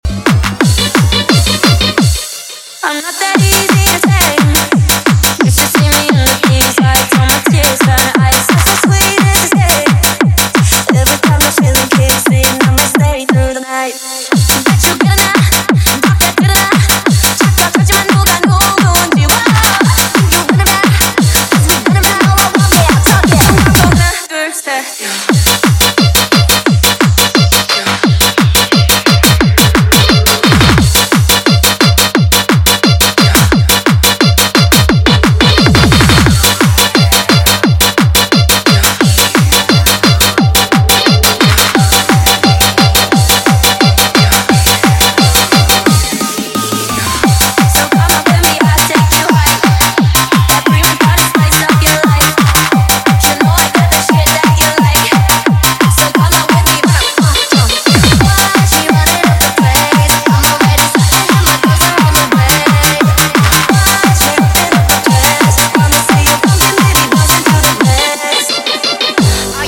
Makina